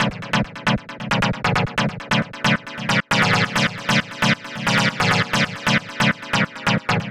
Stab 135-BPM B.wav